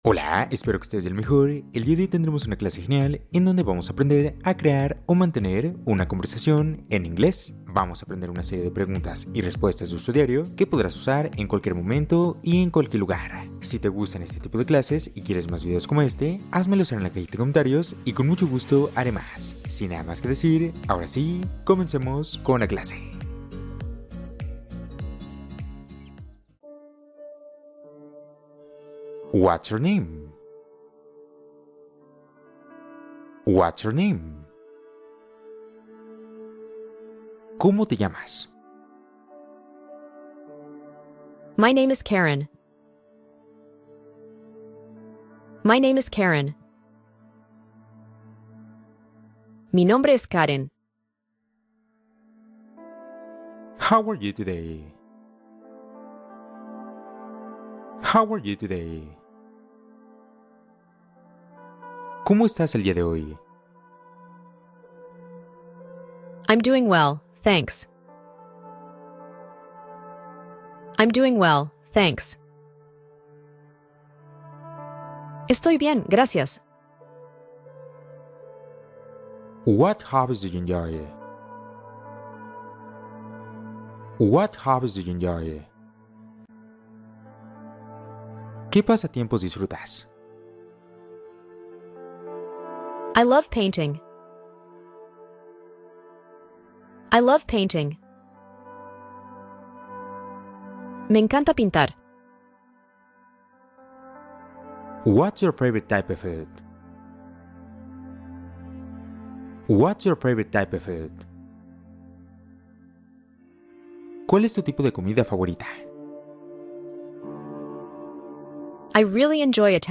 Conversación guiada para reforzar listening y fluidez en inglés